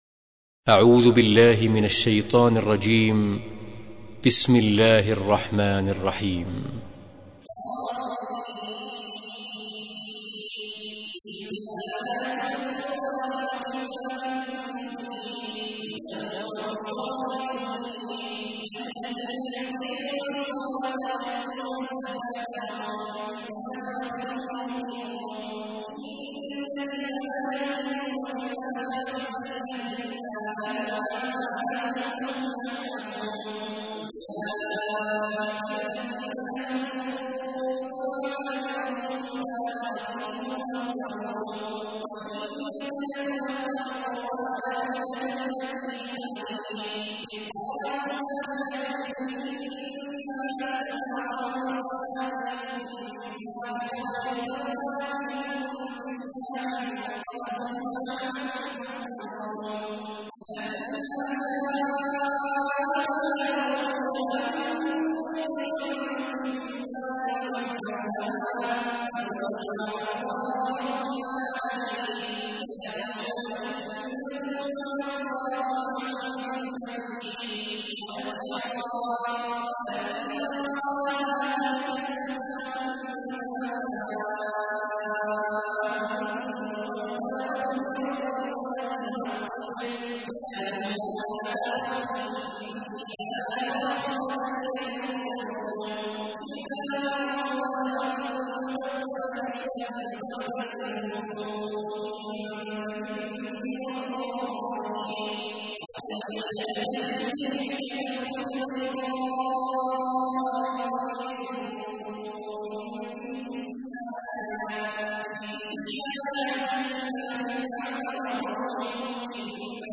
تحميل : 27. سورة النمل / القارئ عبد الله عواد الجهني / القرآن الكريم / موقع يا حسين